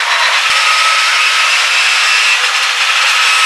rr3-assets/files/.depot/audio/sfx/transmission_whine/tw_offhigh.wav